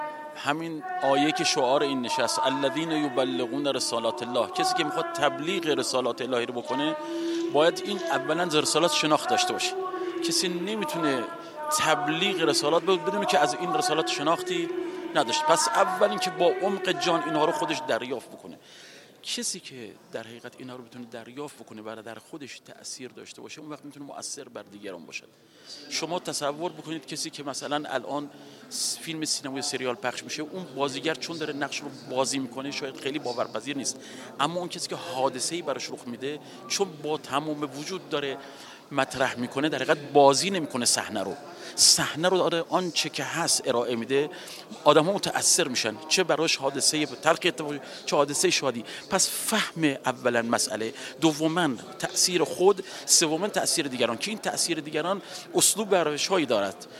سه تن از اساتید پیشکسوت قرآن که در نوزدهمین نشست تخصصی شورای عالی قرآن با عنوان «قاری قرآن؛ مبلغ پیام‌های الهی» حضور داشتند، ویژگی قاری که به امر تبلیغ رسالات‌الله می‌پردازد، تبیین کردند.